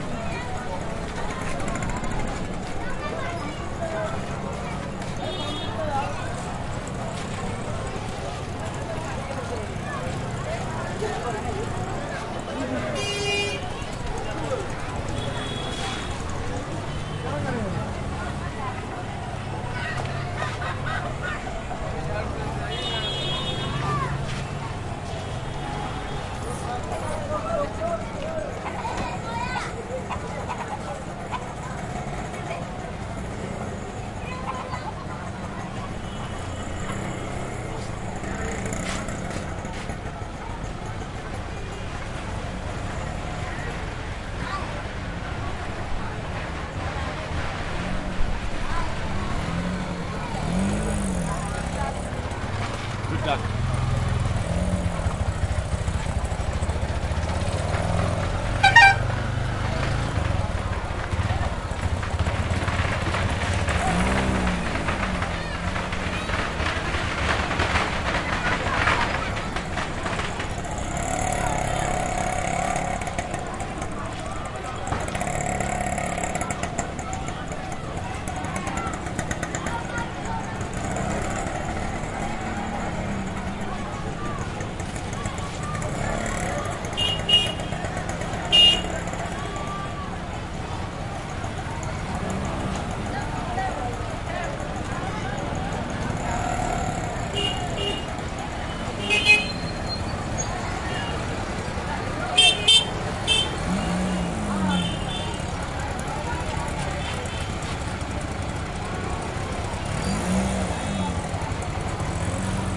汽车交通
描述：在干燥的街道上适度的汽车交通
标签： 道路 交通
声道立体声